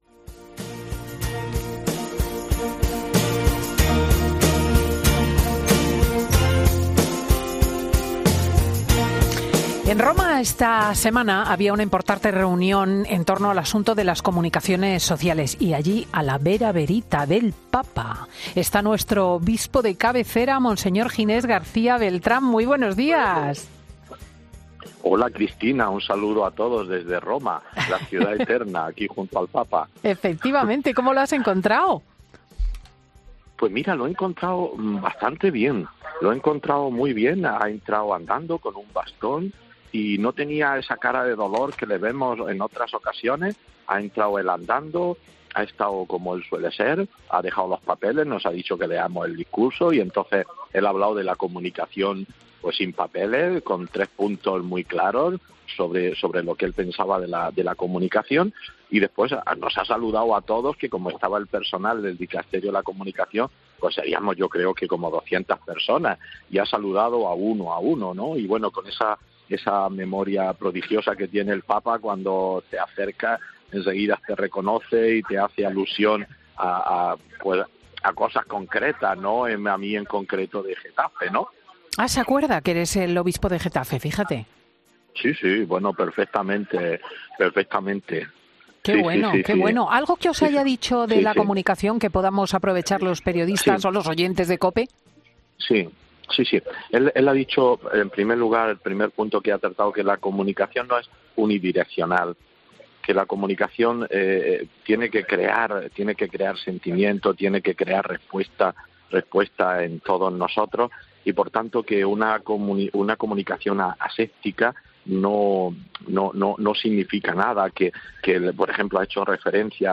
Monseñor Ginés explica en Fin de Semana la inquietud del Papa con el cambio climático, coincidiendo con la Cumbre del Clima que se celebra en Egipto